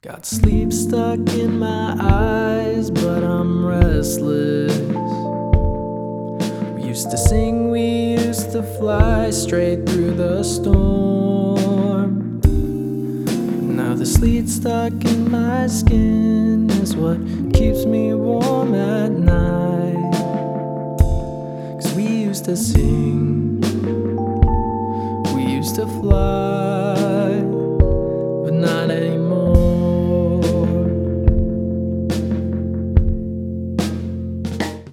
Drums
Bass
Piano